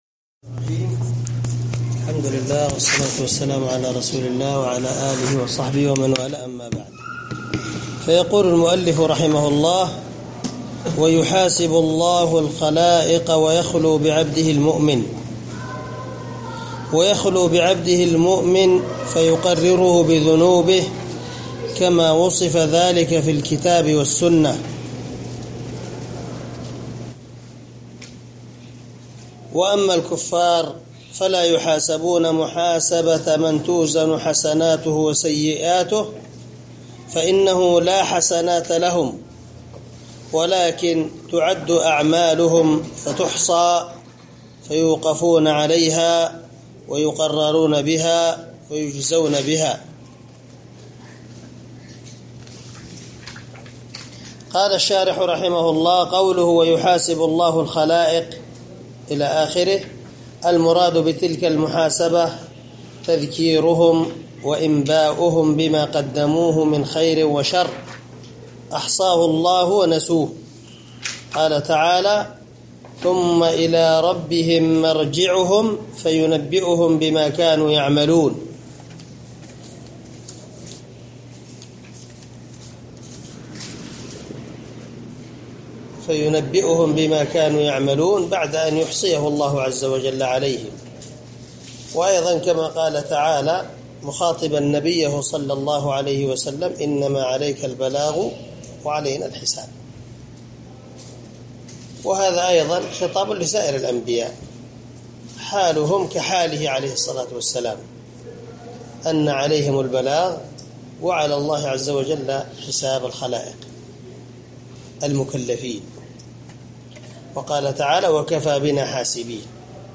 سلسلة_الدروس_العلمية
دار الحديث- المَحاوِلة- الصبيحة.